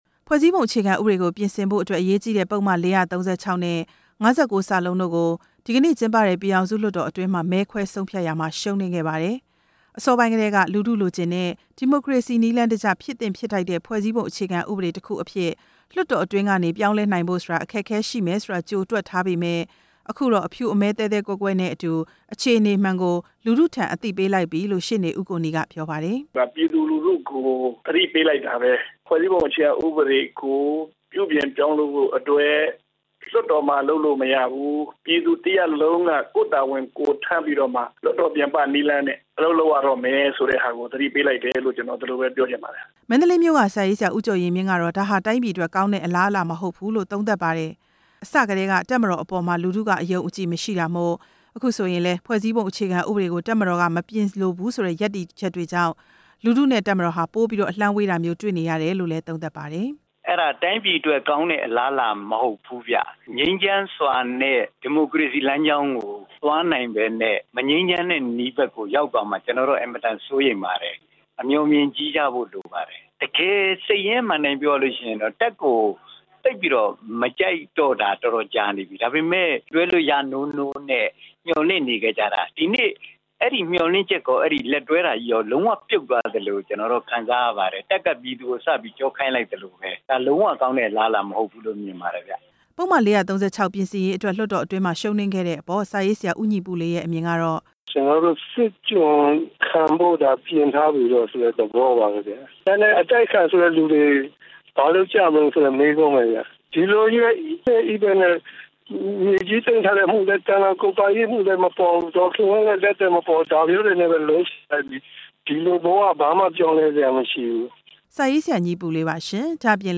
ပြည်သူလူထု တုန့်ပြန်ပြောကြားချက်